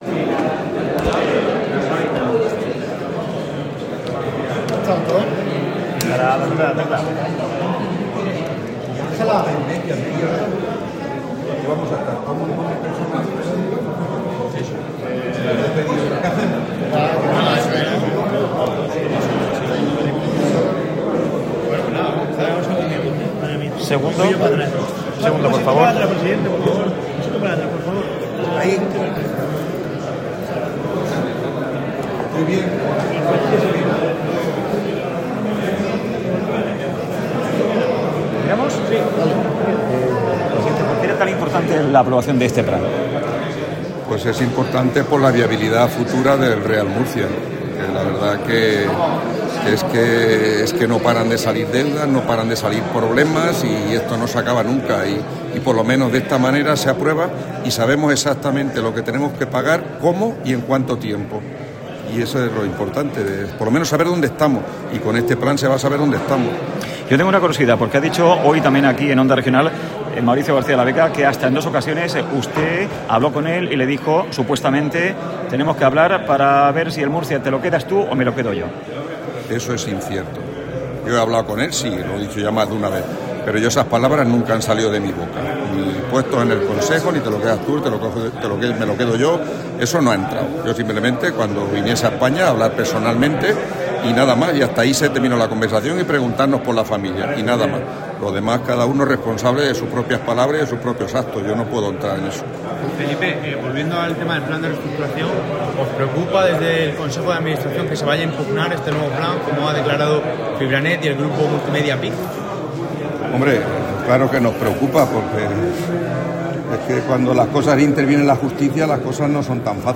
Canutazo